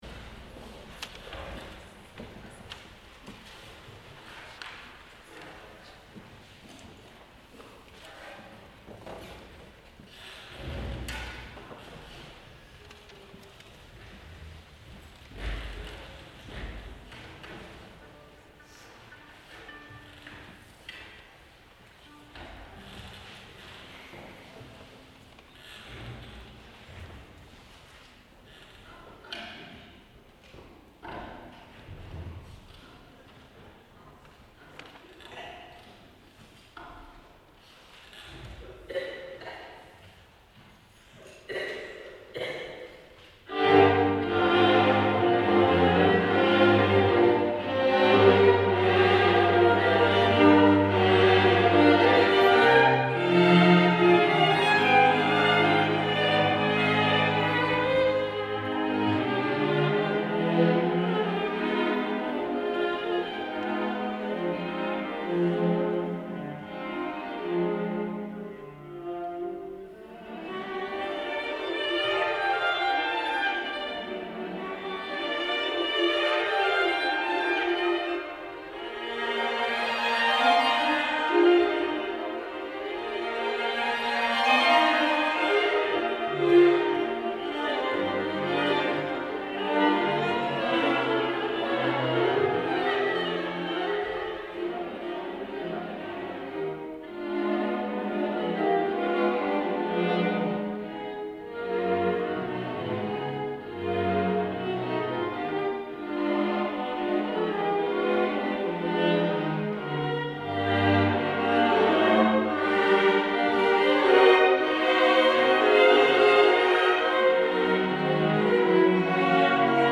Emerging Artists concert July 11, 2013 | Green Mountain Chamber Music Festival
violin
viola
cello
Animé et très décidé Assez vif et bien rythmé Andantino, doucement expressif Très modéré – En animant peu à peu – Très mouvementé et avec passion